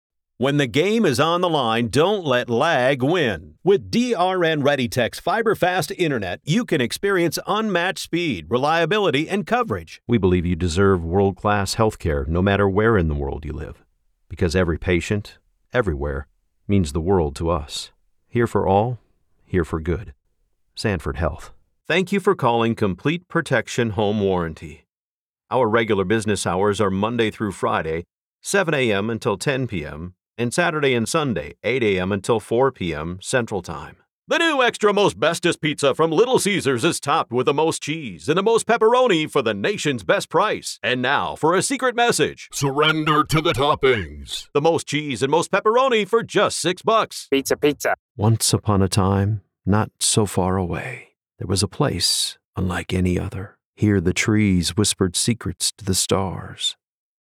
Male
English (North American)
Adult (30-50), Older Sound (50+)
Articulate and professional with the flexibility to deliver an energetic, live broadcast sound as well as a personal and sincere US Midwest vibe.
Radio Commercials